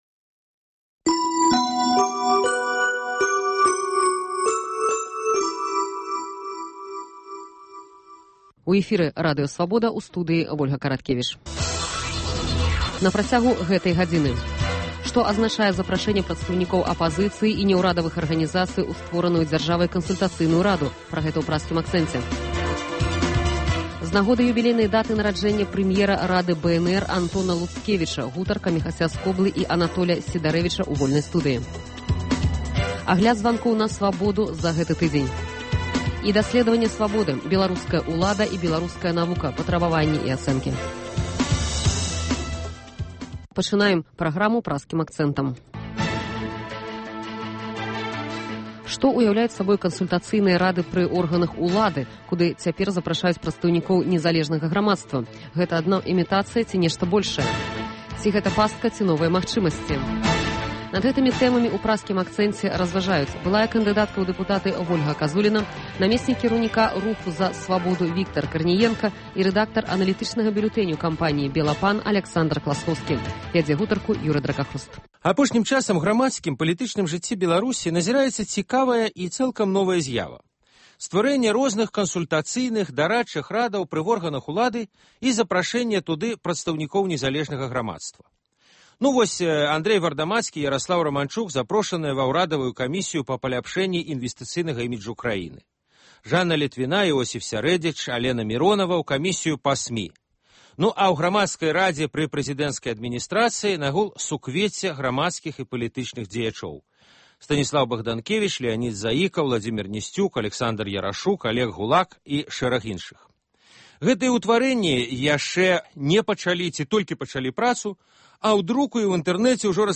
Круглы стол аналітыкаў, абмеркаваньне галоўных падзеяў тыдня